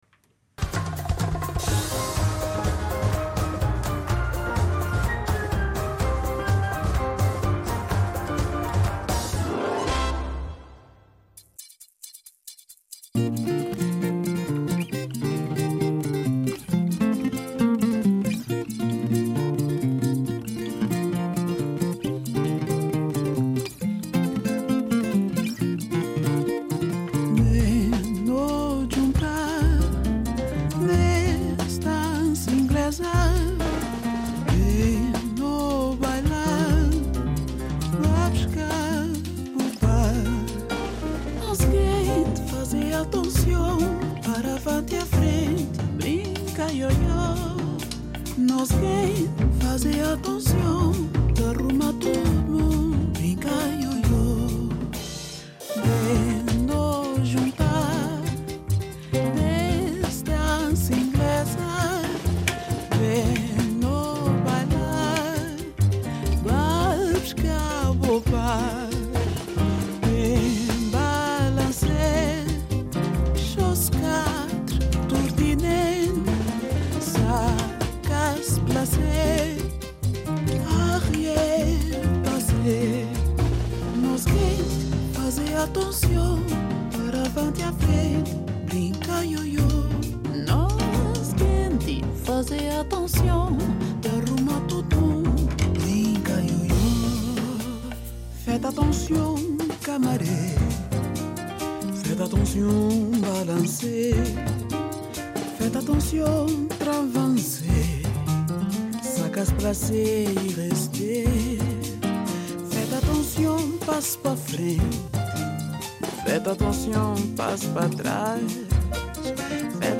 Oggi con Marino Niola parliamo del verde per scoprire in che senso è considerato un colore che si muove fra simbolismo ed ambiguità.